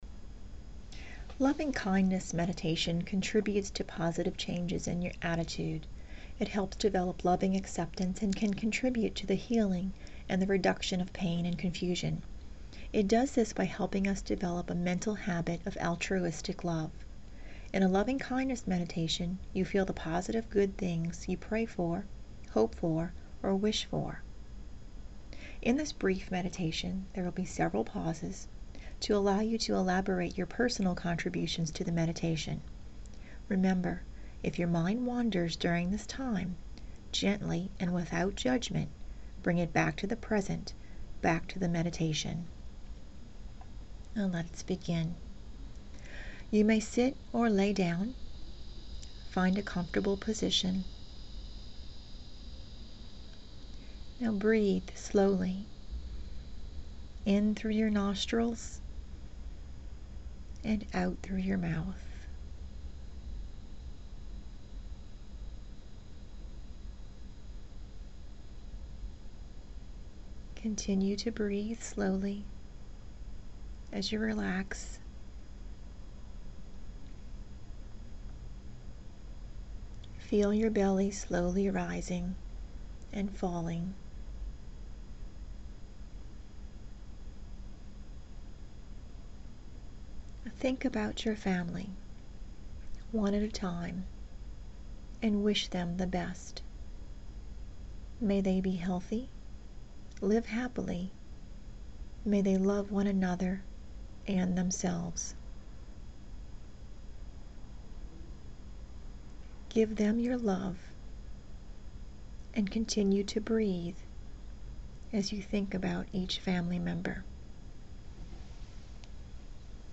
Brief Loving Kindness Meditation — approximately five minutes
brief-lovingkindness-meditation.mp3